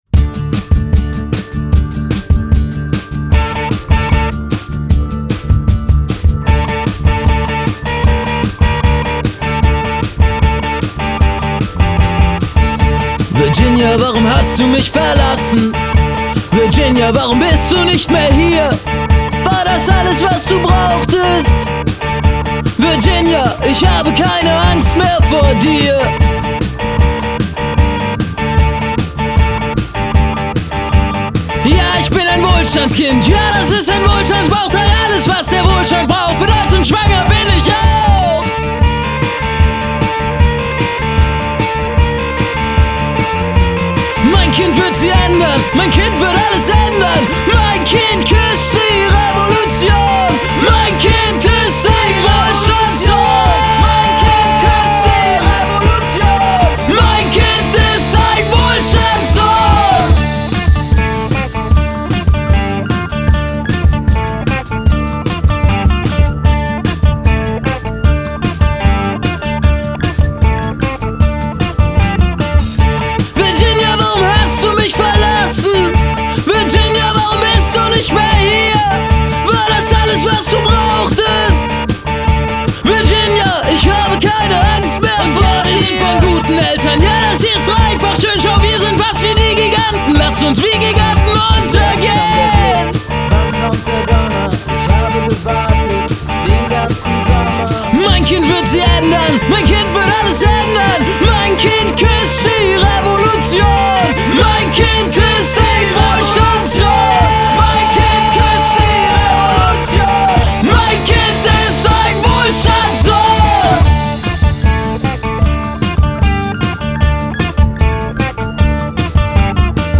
Genre: Alt. Rock.